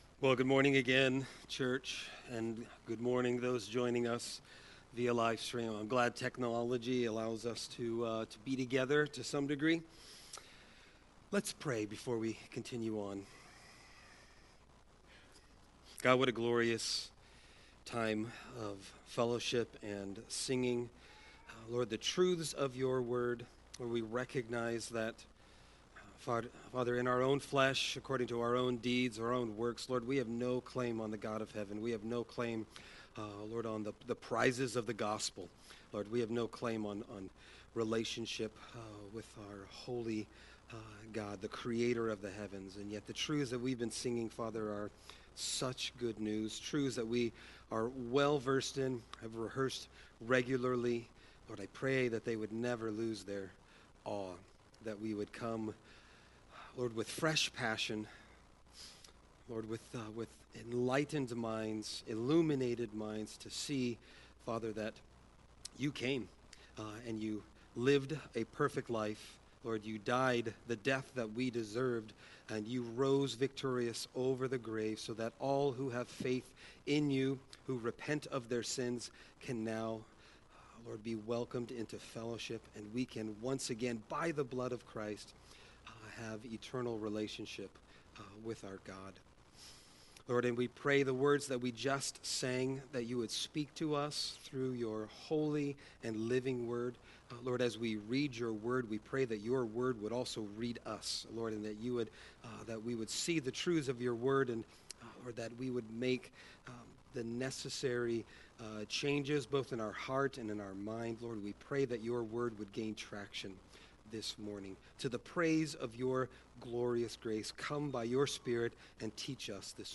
Sermon Text: Ephesians 2:11-16